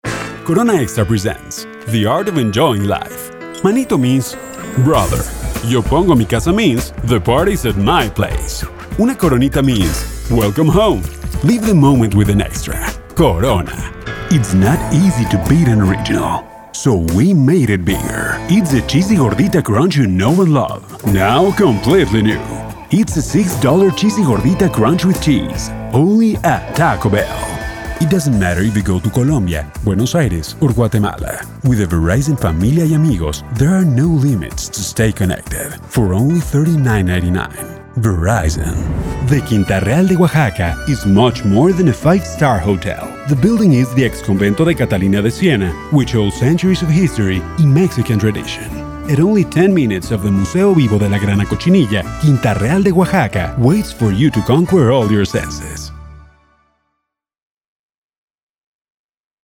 Demos